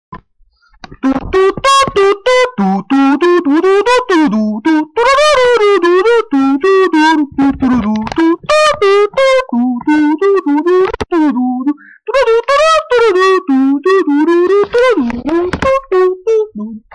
[SOLVED!]Trance song ID from humming/singing
The humming isn't much help